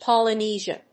音節Pol・y・ne・sia 発音記号・読み方
/pὰləníːʒə(米国英語), p`ɔləníːʒə(英国英語)/
Polynesia.mp3